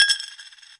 金属 "一角钱掉了一个
描述：一角钱掉进一个空锡罐。掉落的硬币比以前的录音多。用立体声话筒录音，尽可能地去除背景噪音。
标签： 硬币 掉线
声道立体声